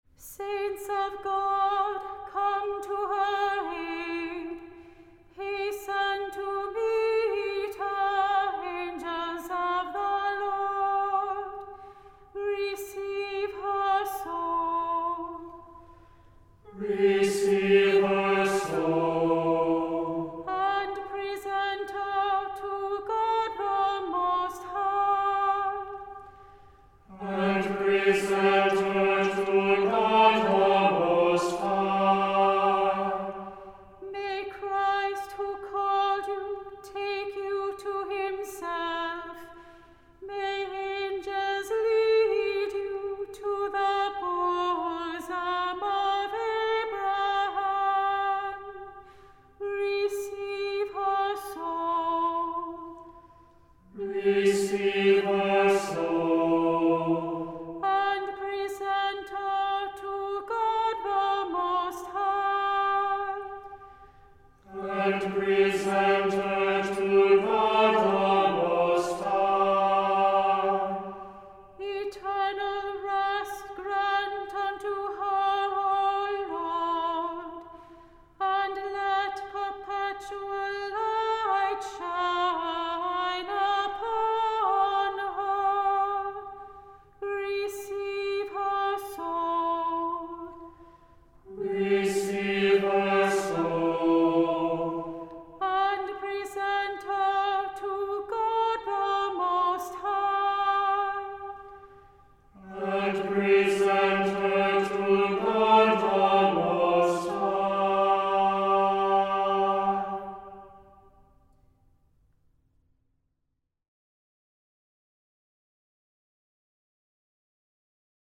Voicing: Unison; Cantor; Assembly